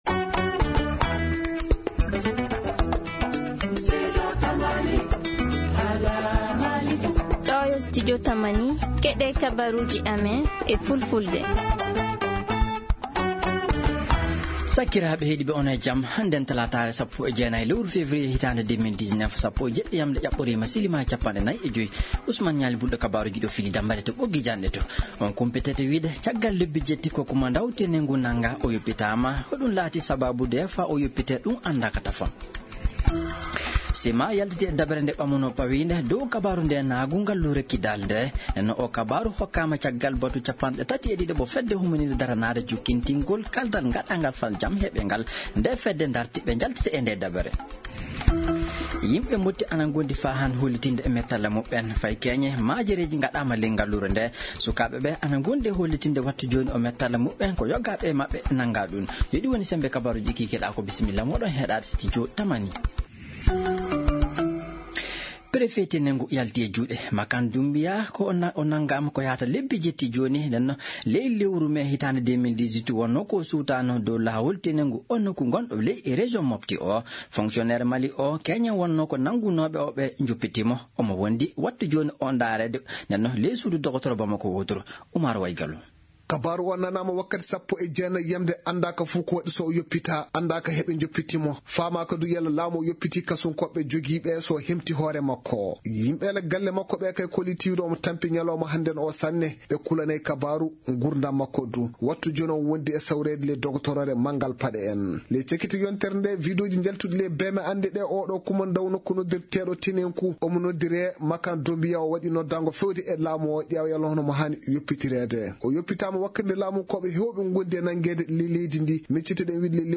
Journal en français: Télécharger